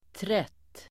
Uttal: [tret:]